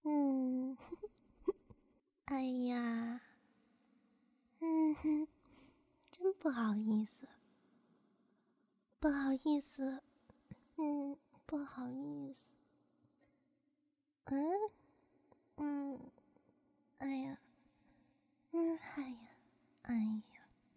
害羞xx1.wav
害羞xx1.wav 0:00.00 0:20.77 害羞xx1.wav WAV · 1.7 MB · 單聲道 (1ch) 下载文件 本站所有音效均采用 CC0 授权 ，可免费用于商业与个人项目，无需署名。
人声采集素材/人物休闲/害羞xx1.wav